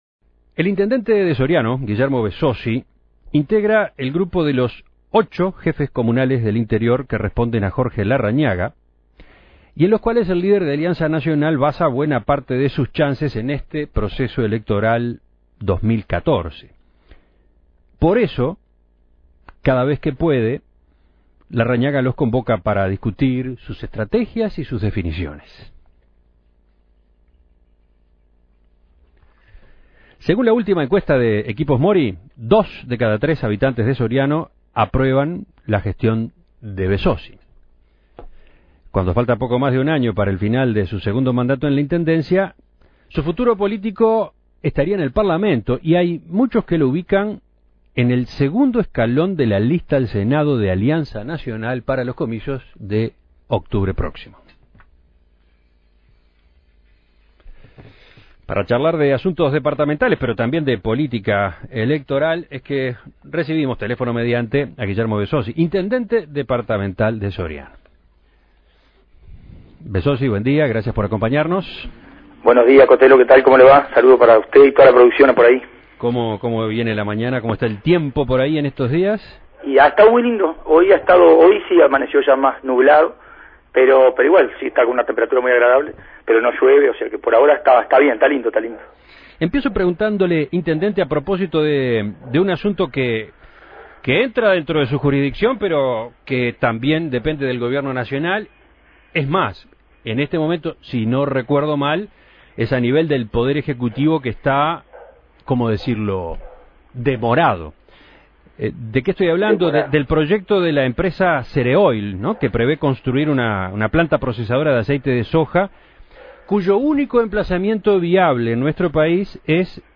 El intendente de Soriano, Guillermo Besozzi, se mostró molesto ante esta situación y ante la falta de respuesta por parte del Gobierno. En conversación con En Perspectiva, el jefe comunal aseguró que, por lo visto, a ningún partido político le preocupa que quede "en la nebulosa" una inversión relevante que agrega valor a la producción de soja y genera decenas de empleos.